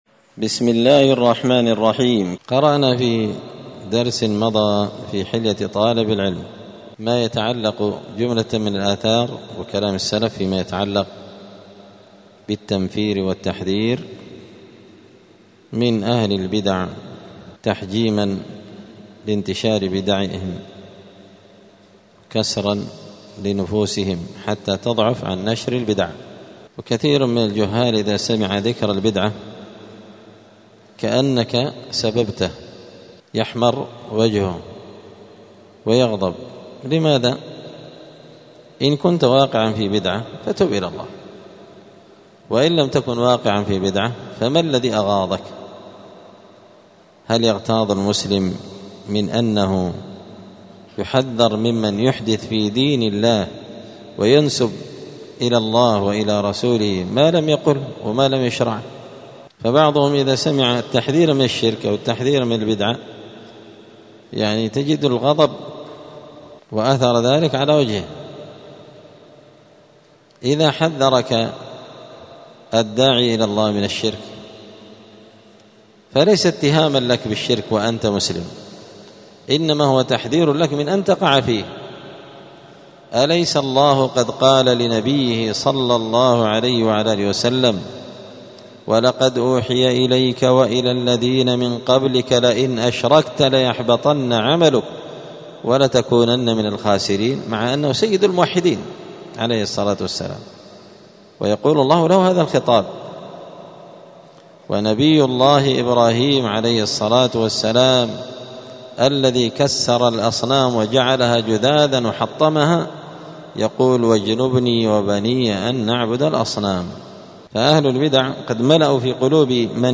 الخميس 10 ربيع الثاني 1447 هــــ | الدروس، حلية طالب العلم، دروس الآداب | شارك بتعليقك | 13 المشاهدات